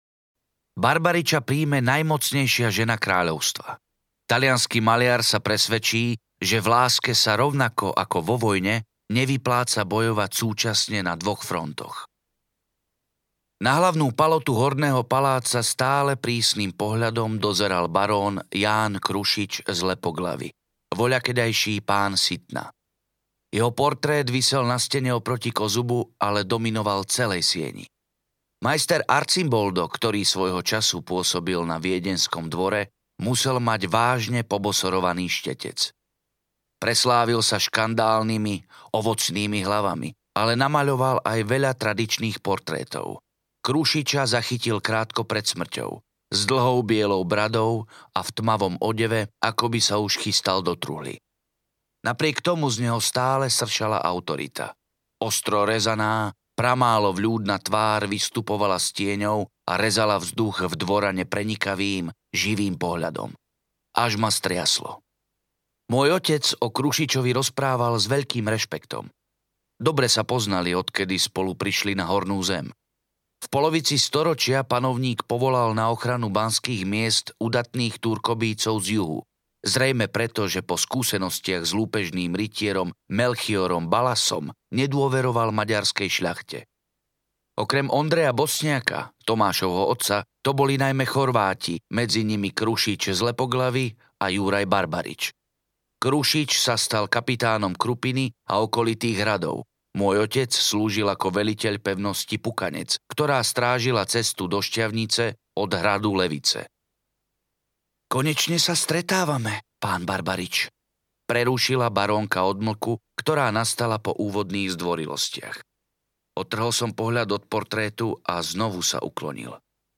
Vlk a dýka audiokniha
Ukázka z knihy
vlk-a-dyka-audiokniha